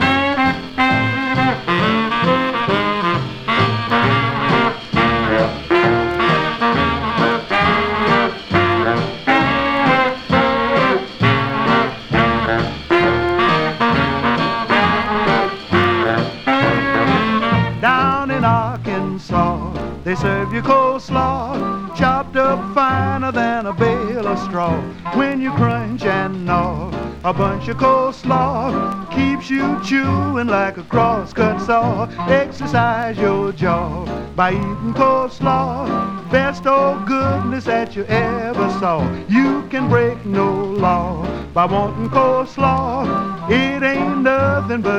黒光りするような魅力溢れる演奏、素敵さと洒落た印象も含んだ好内容。
Jazz, Rhythm & Blues　USA　12inchレコード　33rpm　Mono